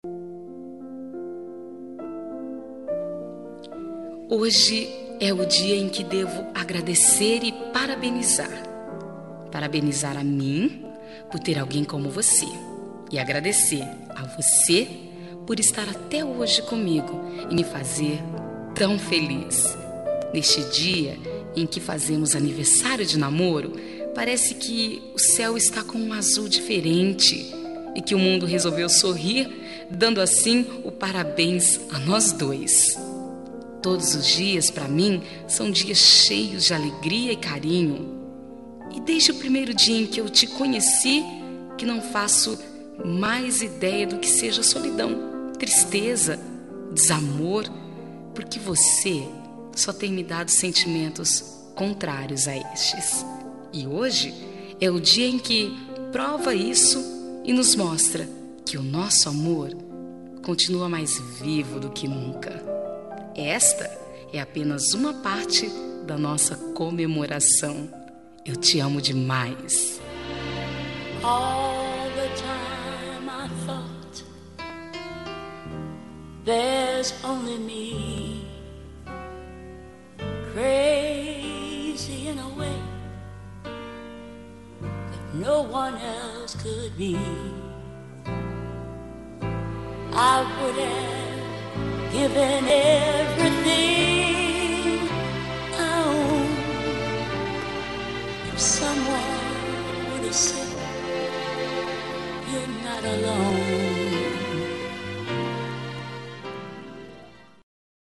Aniversário de Namoro – Voz Feminina – Cód: 01748